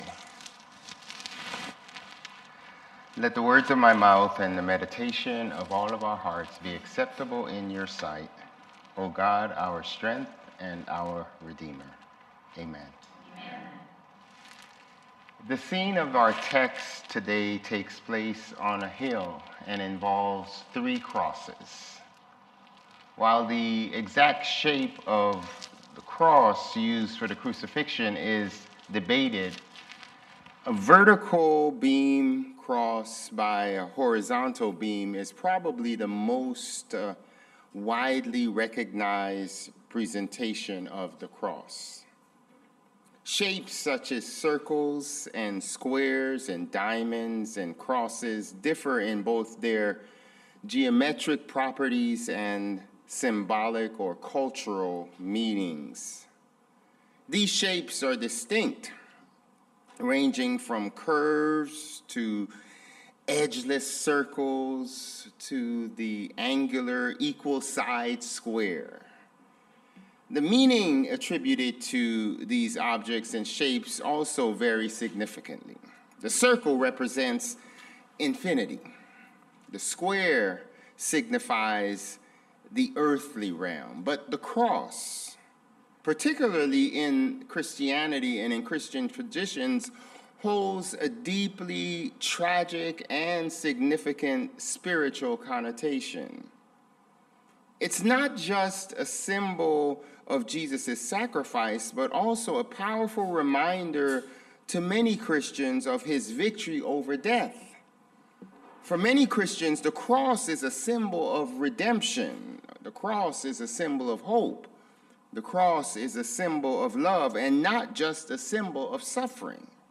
Sermons | Bethel Lutheran Church
July 20 Worship
This sermon explores the symbolism and spiritual significance of the three crosses at Jesus' crucifixion. It delves into the contrast between human justice and divine mercy, the universal reality of death, and the transformative power of Christ's sacrifice. The cross of Jesus, though a symbol of suffering and its tragic nature, represents hope, love, and redemption for Christians.